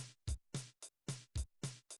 Im Folgenden sind einige generierte Samples, welche durch den Encoder mit den zu sehenden Koordinaten erstellt worden sind, zu hören.